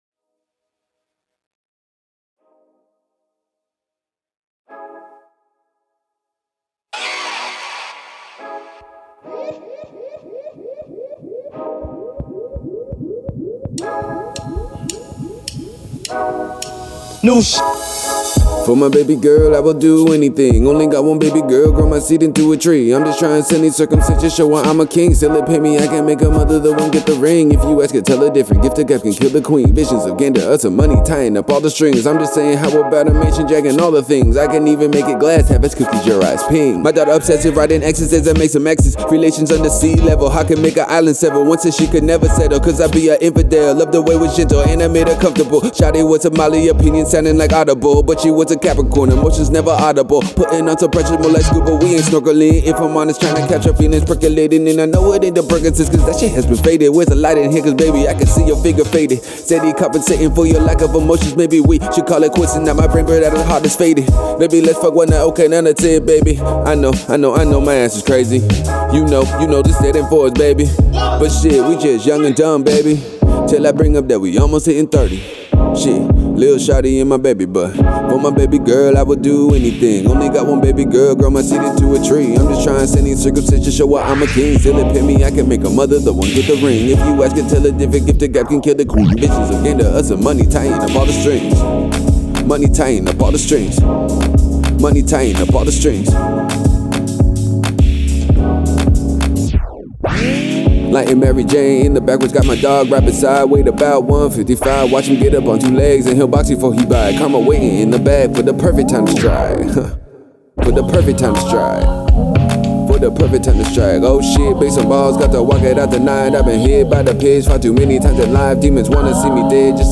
luxury rap & country crossover.